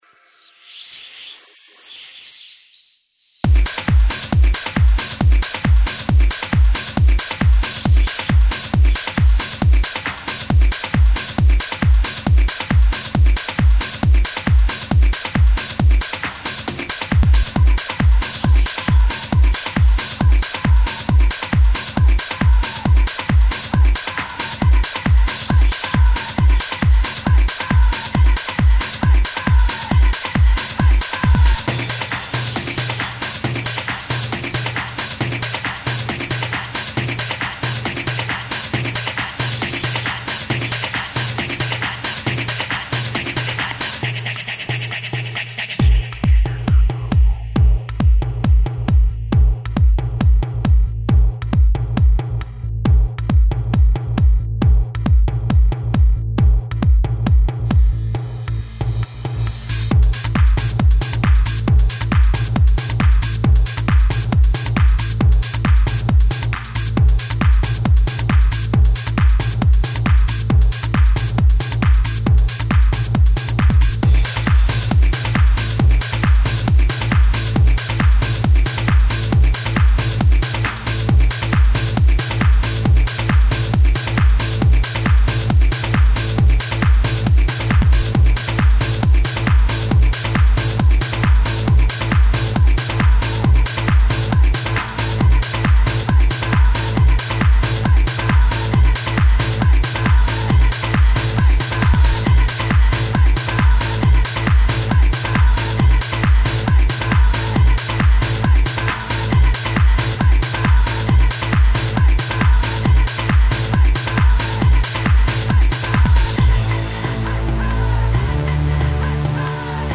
Электронная музыка Транс Trance